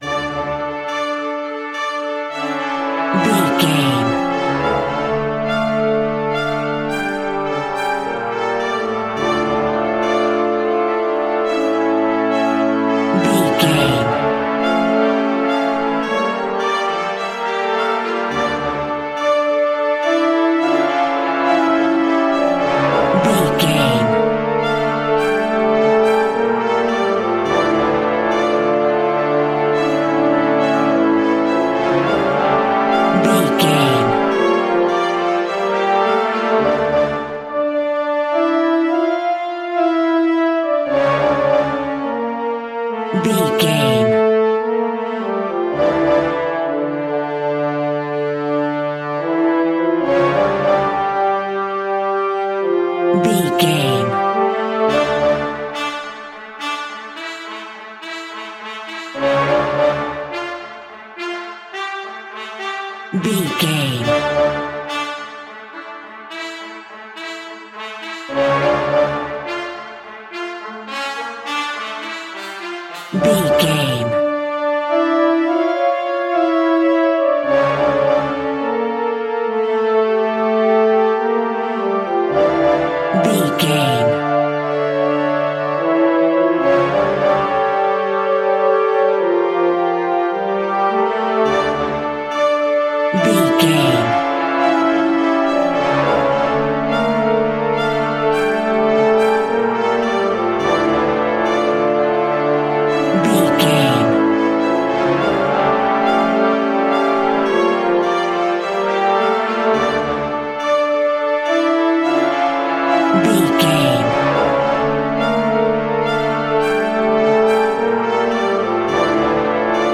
Brass Horror Music.
In-crescendo
Aeolian/Minor
D
scary
ominous
dark
haunting
eerie
synthesizer
orchestra
pads